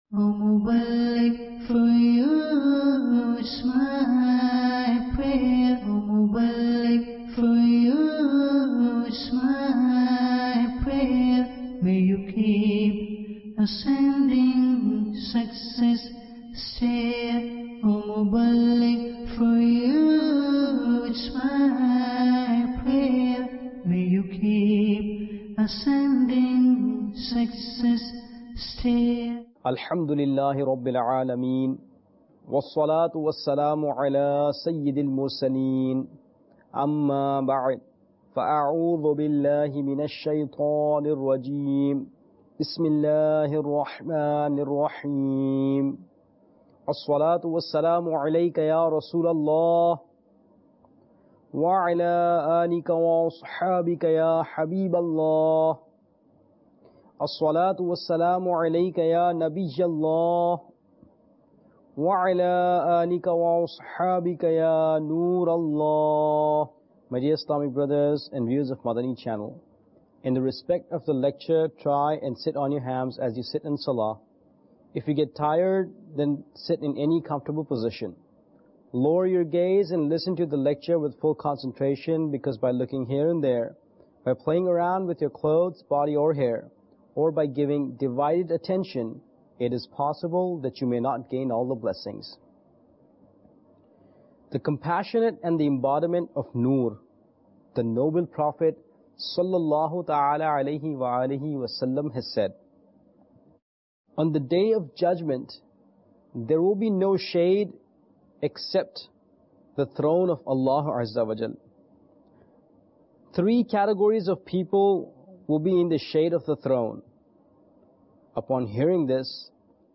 To obtain more knowledge watch the Sunnah inspired discourse.